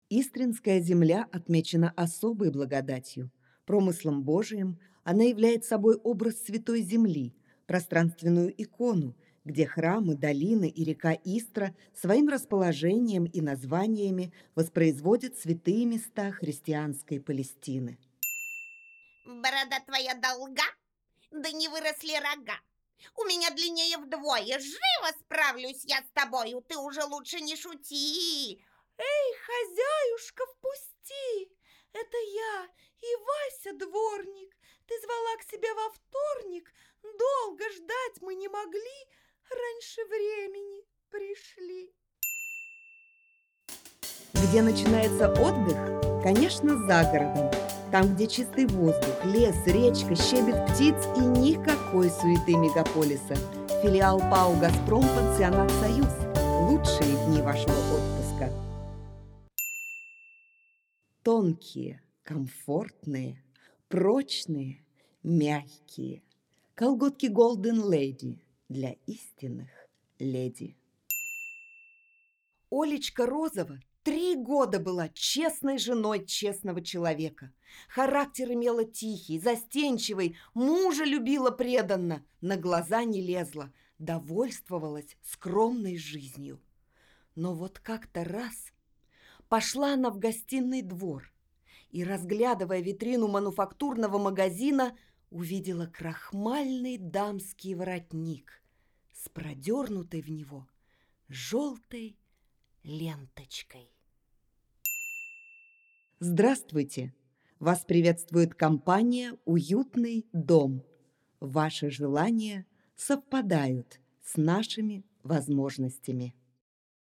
Скачать демо диктора